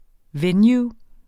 Udtale [ ˈvεˌnjuː ]